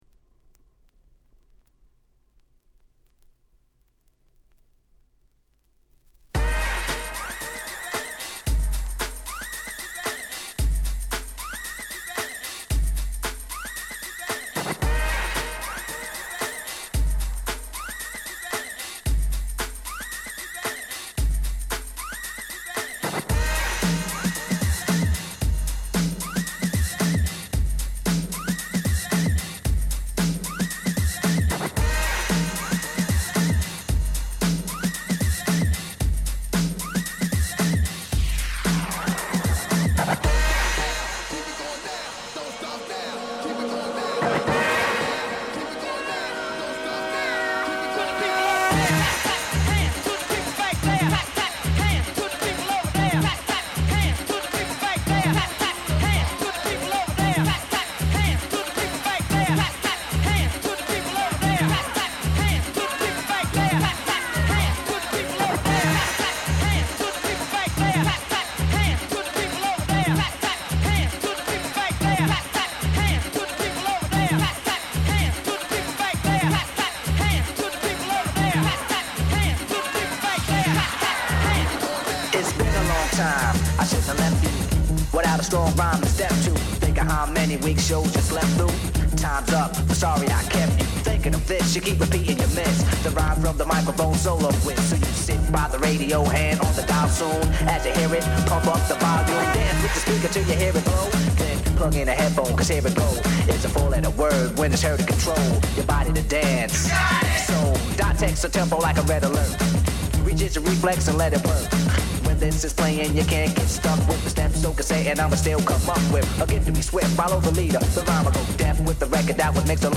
This is my first mix, the funkiest and the most appreciated.